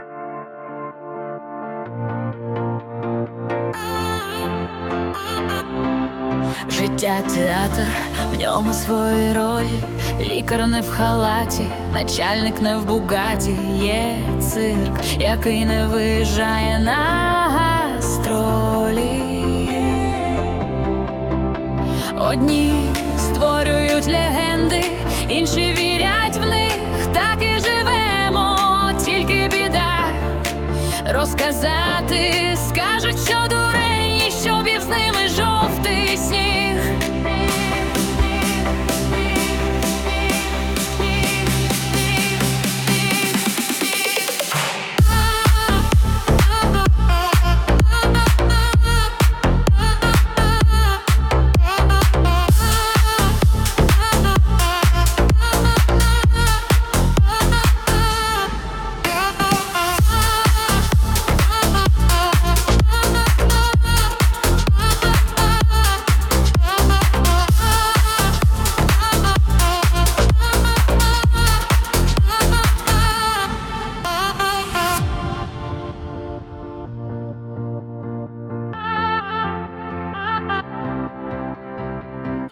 Життя-театр (+🎧 музична версія)